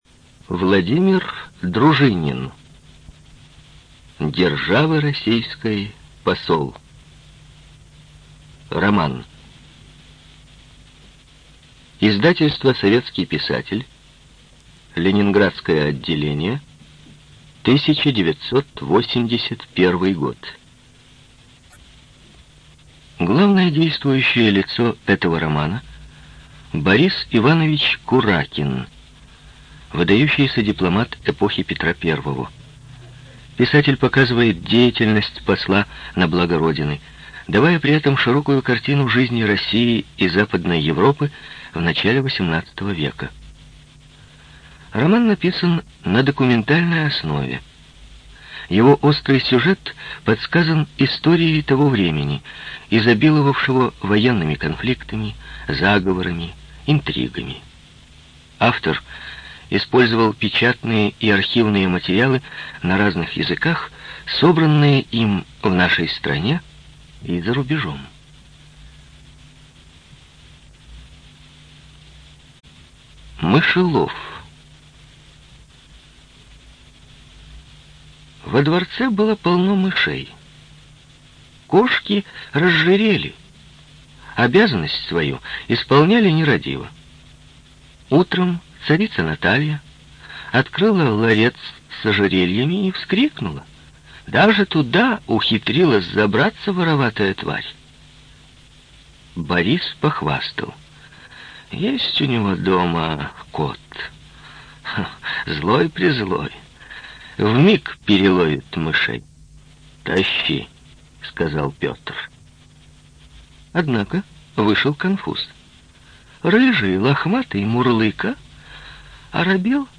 ЖанрИсторическая проза
Студия звукозаписиРеспубликанский дом звукозаписи и печати УТОС